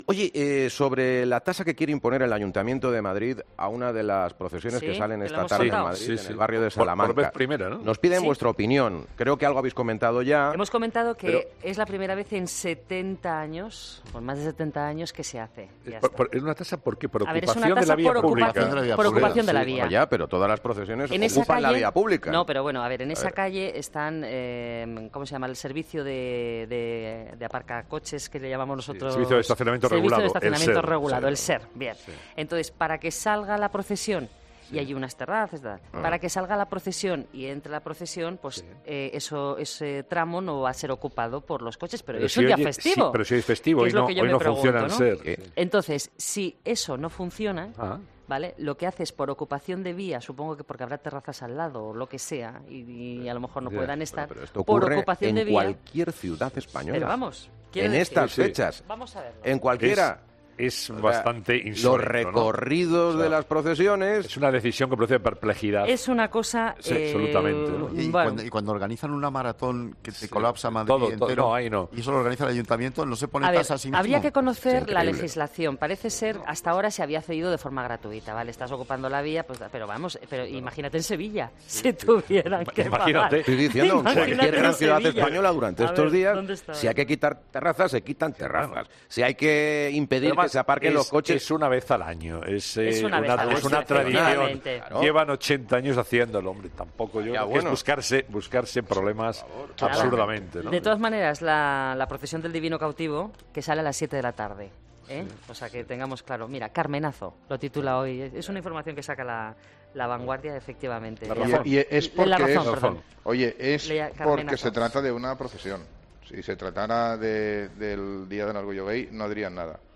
Escucha la tertulia en 'Herrera en COPE' Impuesto al Divino Cautivo en Madrid por ocupación de la vía pública